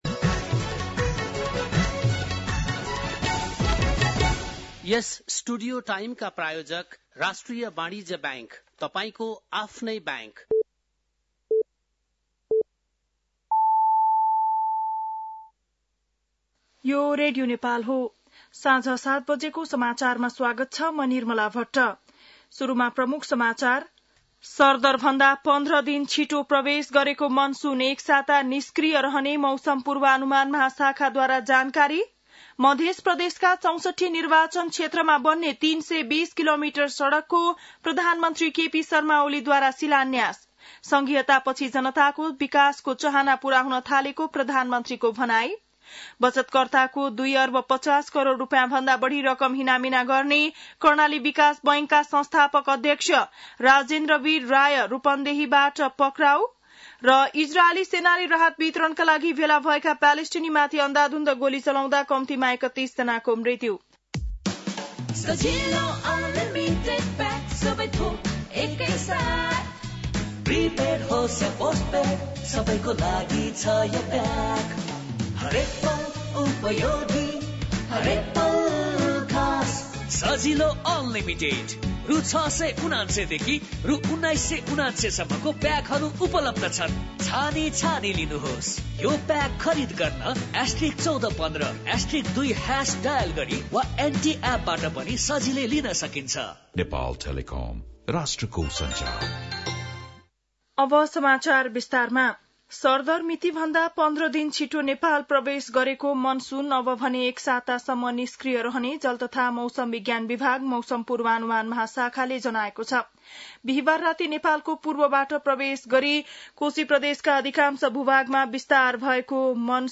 बेलुकी ७ बजेको नेपाली समाचार : १८ जेठ , २०८२
7-pm-nepali-news-2-18-.mp3